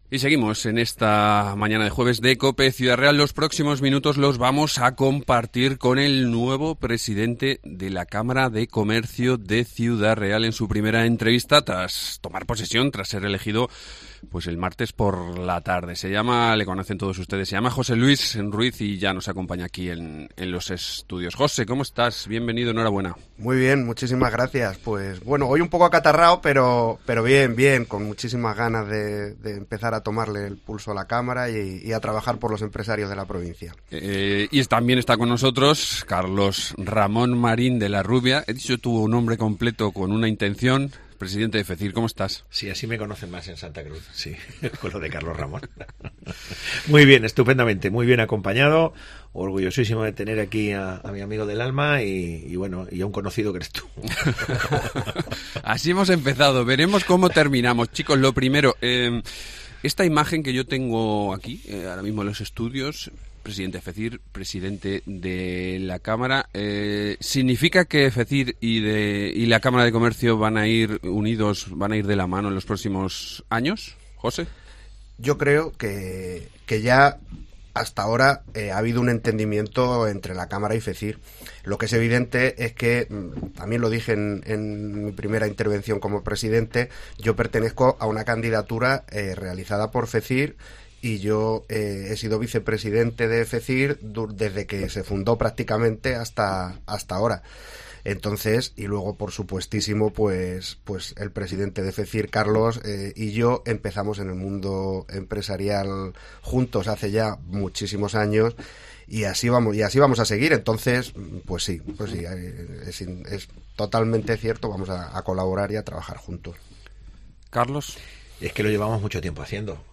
El nuevo presidente de la Cámara ha añadido que la colaboración con las instituciones seguirá "siendo estupenda" Escucha la entrevista completa aquí.